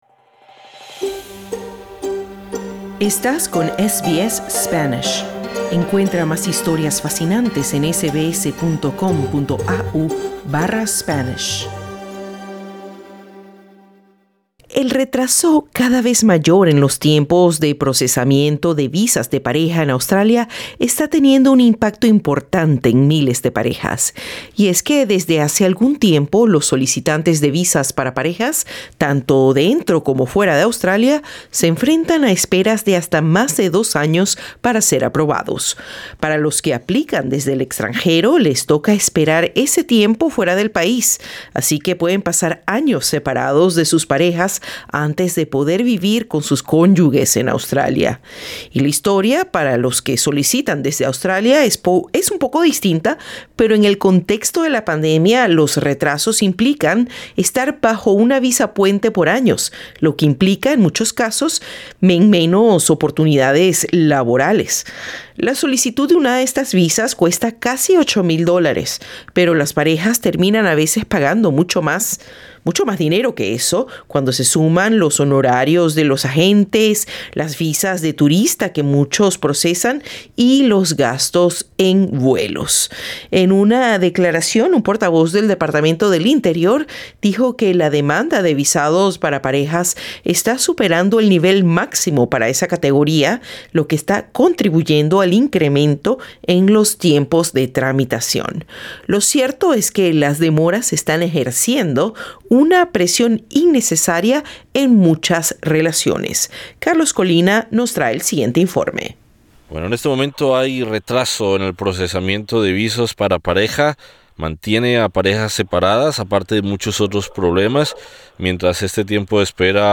El aumento en los retrasos para procesar visas conyugales en Australia está teniendo un fuerte impacto en miles de parejas, que se enfrentan a esperas de hasta más de dos años para ser aprobadas. Escucha los testimonios de un agente de inmigración y una mujer afectada por la espera de su visa.